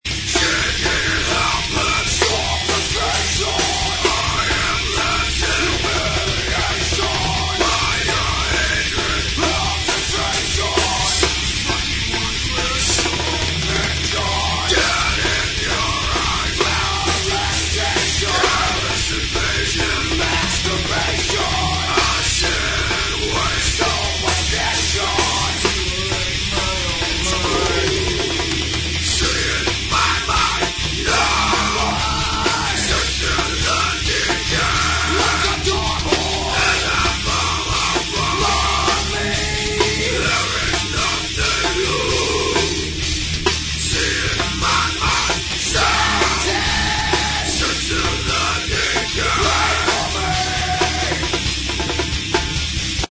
demo 0:53 preview (studio recording)